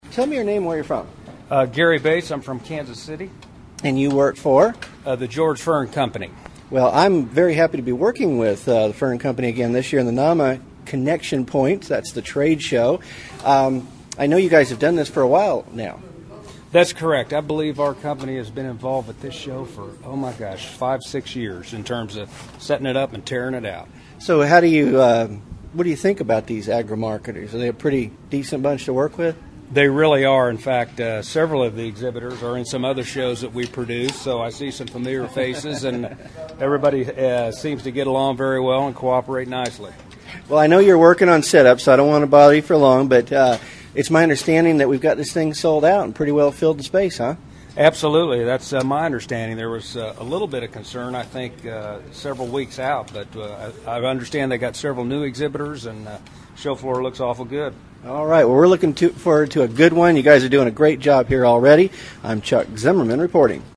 ZimmComm at NAMAZimmComm is on location at the Atlanta Marriott Marquis.